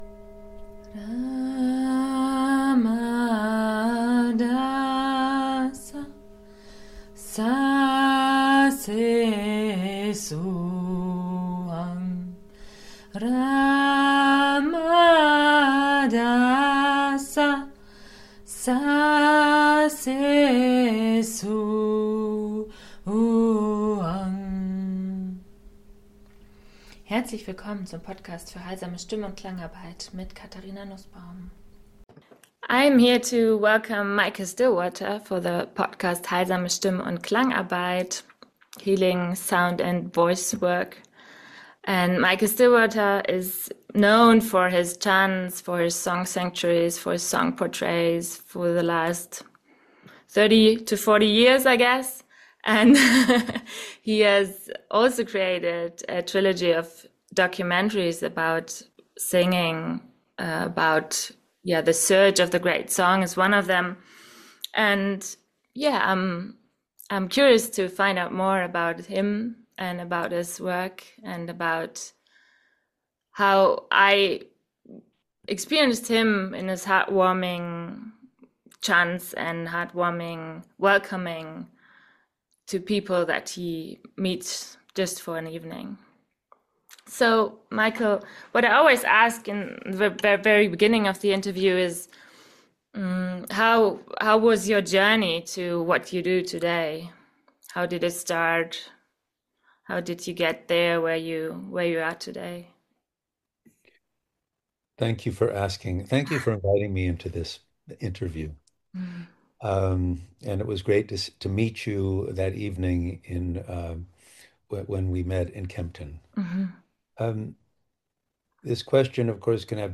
In dem Interview